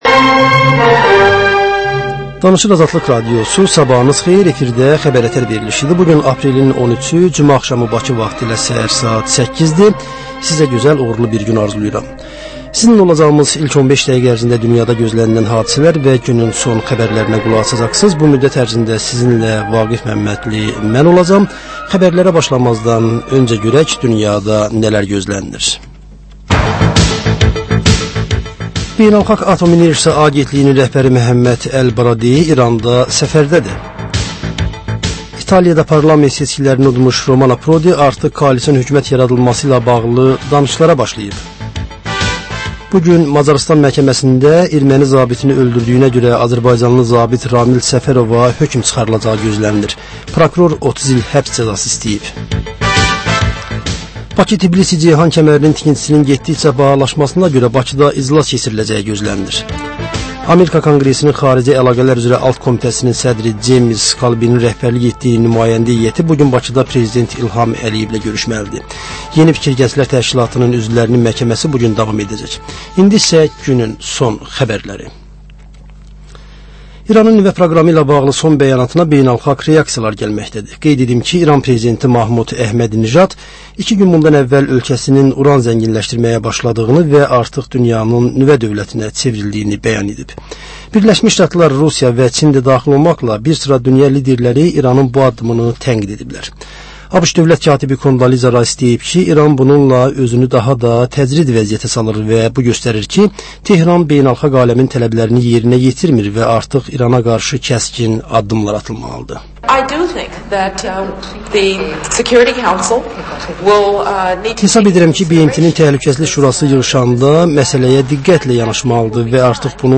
Səhər-səhər, Xəbər-ətər: xəbərlər, reportajlar, müsahibələr